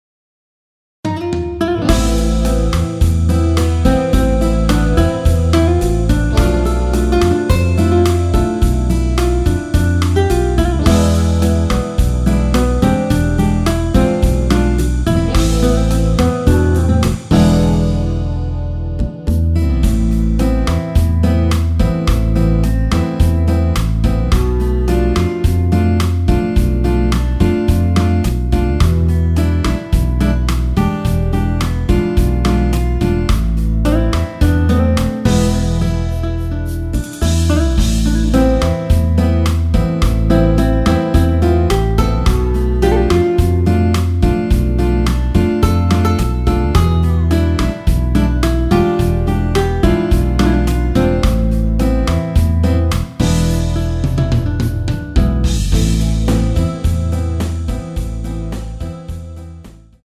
Am
앞부분30초, 뒷부분30초씩 편집해서 올려 드리고 있습니다.
중간에 음이 끈어지고 다시 나오는 이유는